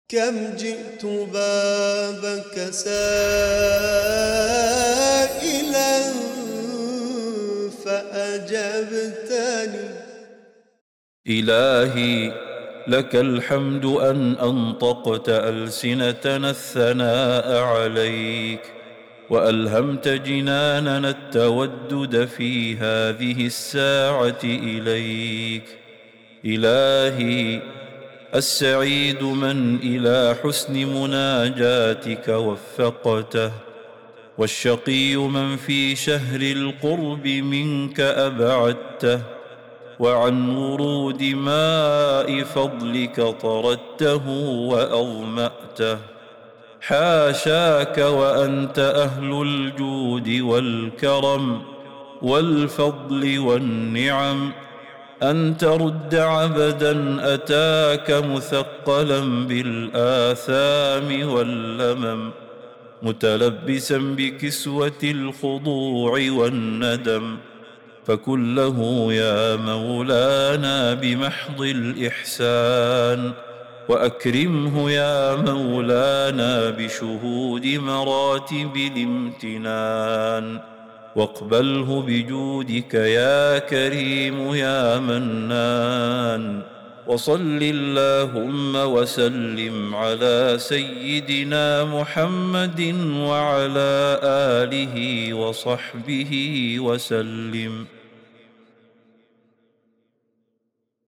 دعاء مؤثر يعبر عن التذلل والانكسار بين يدي الله تعالى، معترفاً بالذنب ومتوسلاً بجوده وكرمه. النص يصور حالة العبد المثقل بالآثام والذي يلجأ إلى ربه طالباً القبول والمغفرة محتملاً بصفات الجود والكرم الإلهية.